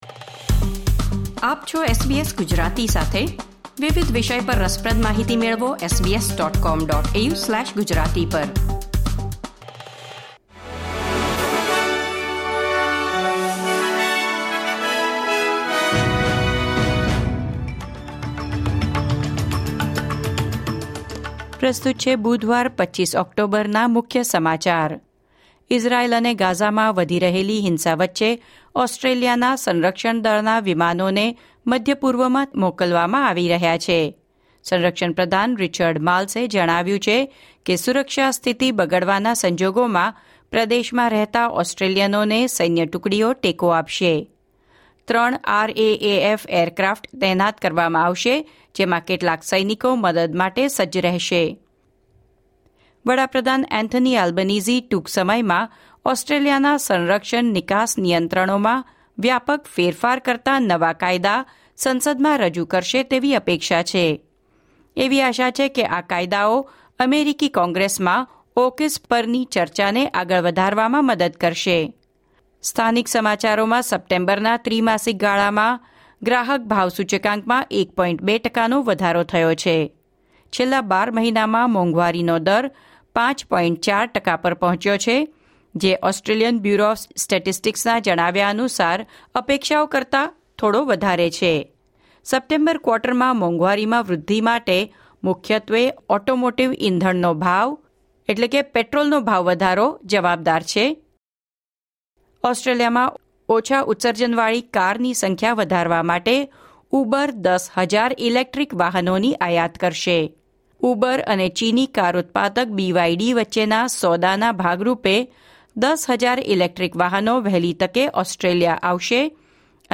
SBS Gujarati News Bulletin 25 October 2023